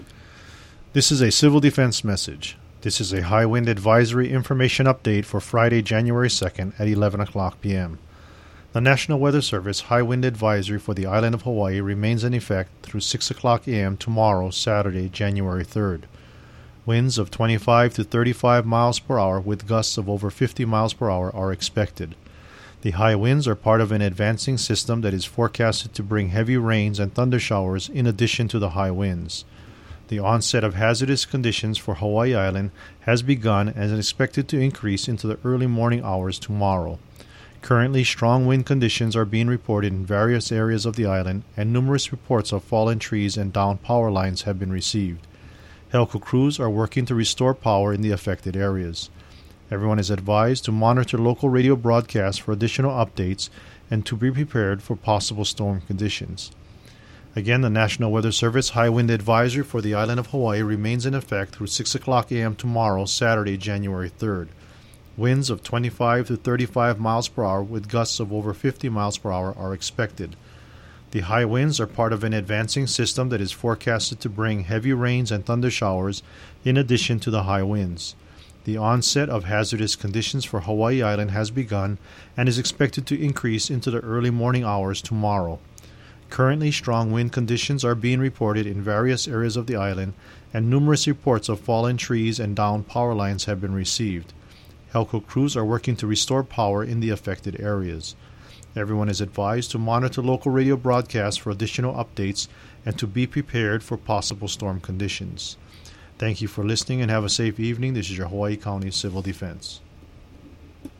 Here is the Civil Defense audio message issued at 11 p.m. on Jan. 2: